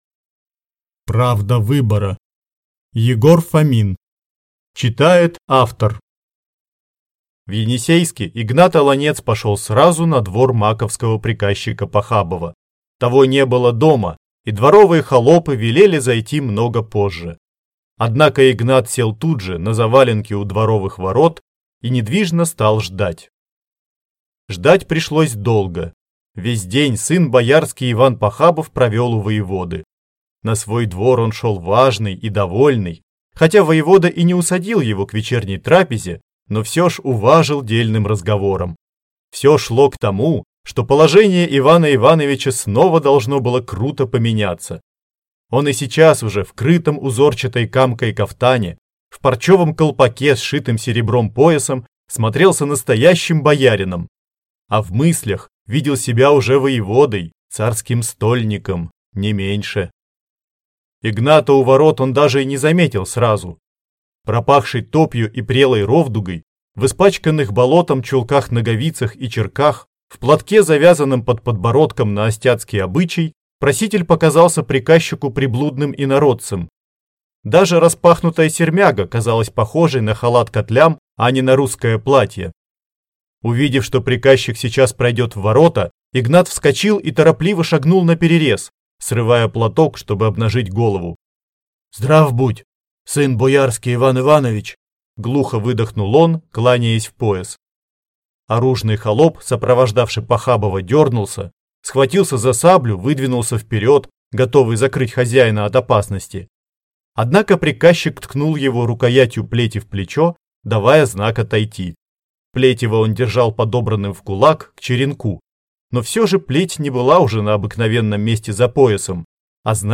Аудиокнига Правда выбора | Библиотека аудиокниг